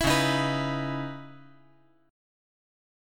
Dbm9 Chord
Listen to Dbm9 strummed